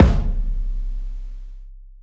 Live_kick_5.wav